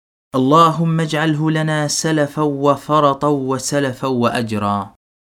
Or an alternate recitation,